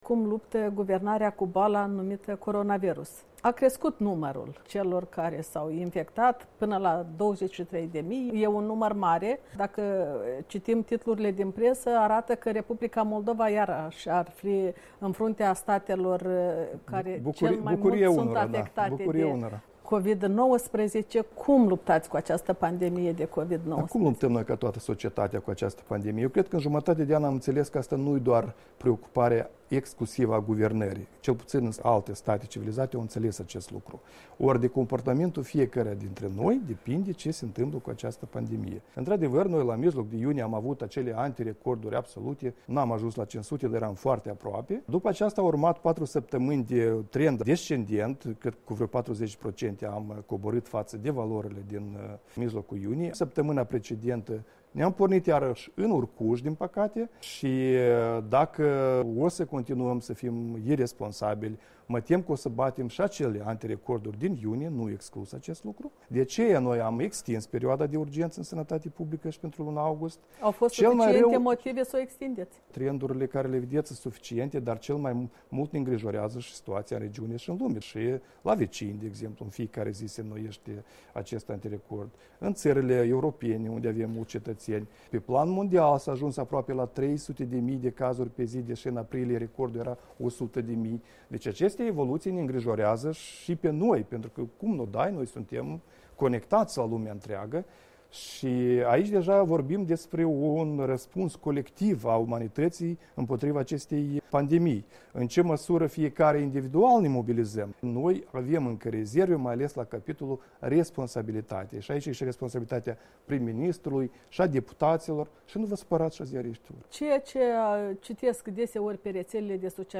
Interviu cu premierul Ion Chicu (partea 2)